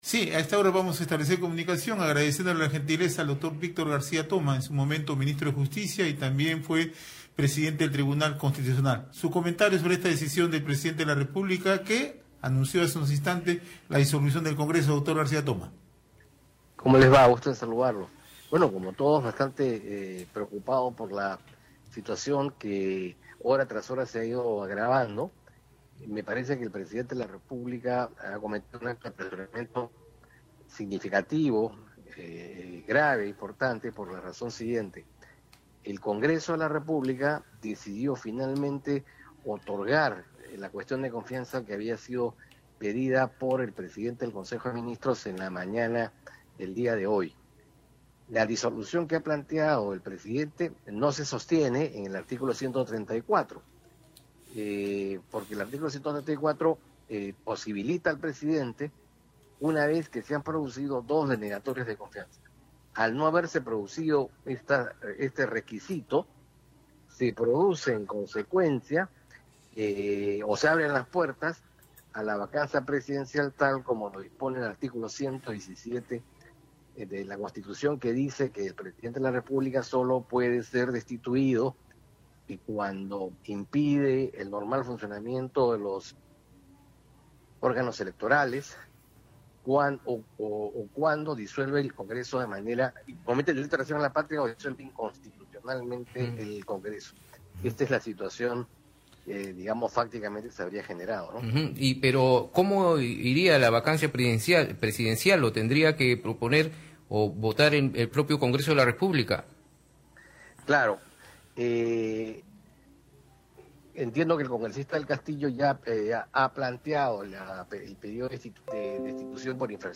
En declaraciones a Red de Comunicación Regional, García Toma explicó que no se ha configurado el requisito de dos gabinetes que no obtienen la cuestión de confianza para cerrar constitucionalmente el Poder Legislativo.